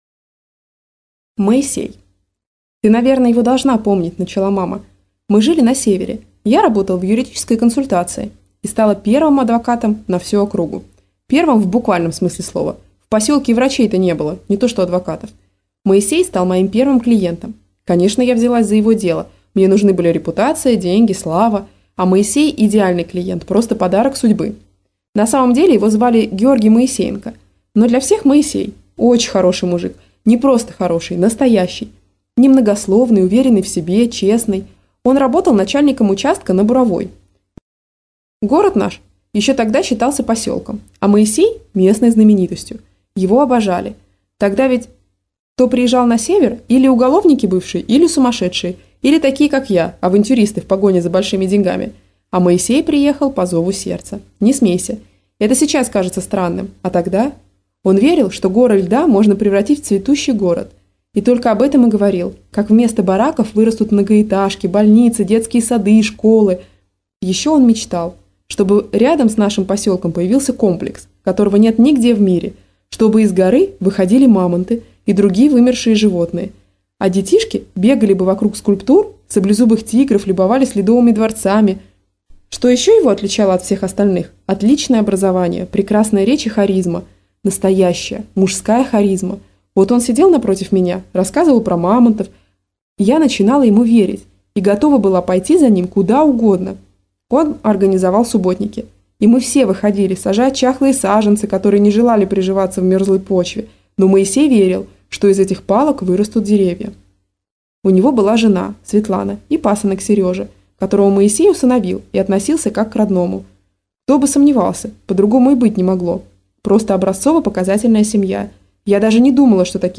Аудиокнига Истории моей мамы - купить, скачать и слушать онлайн | КнигоПоиск